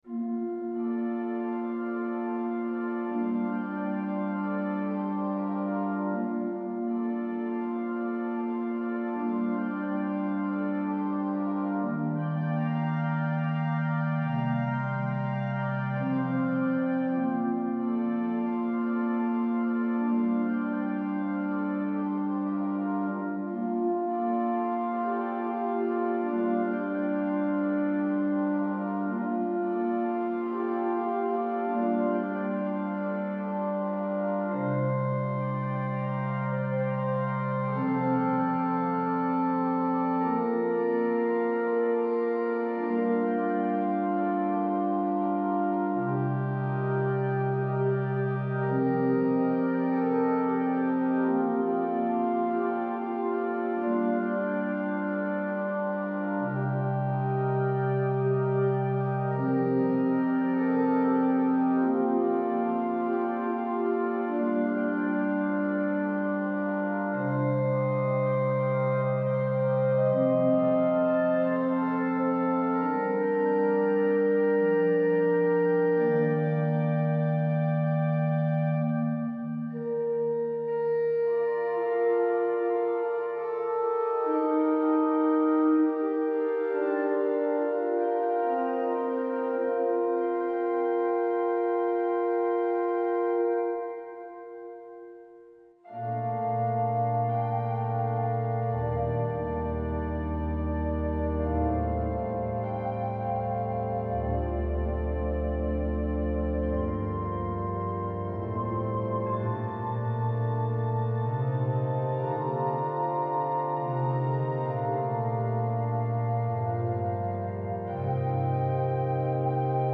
Berceuse
Un bel accompagnement mystique à l’orgue
• Main droite :     cordes
• Main gauche :   flûte
• Pédale :              prestant